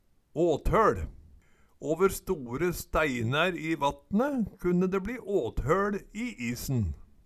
åthøL - Numedalsmål (en-US)